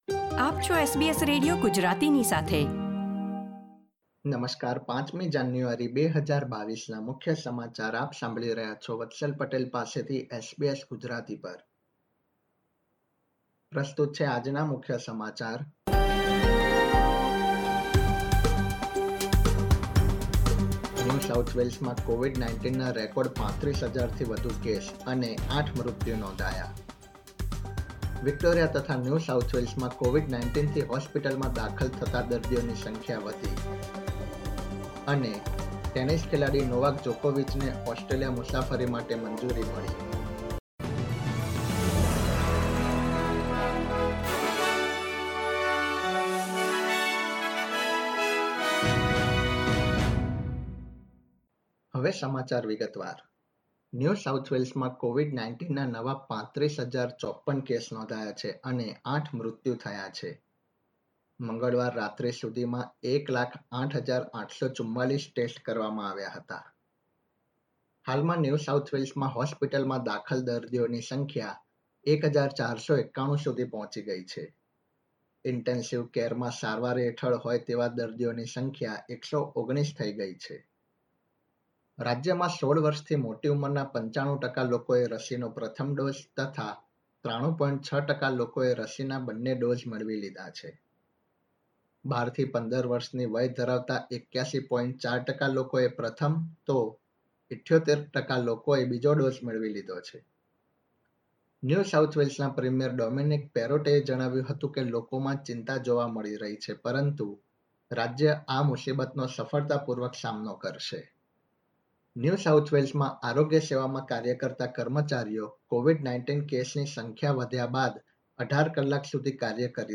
SBS Gujarati News Bulletin 5 January 2022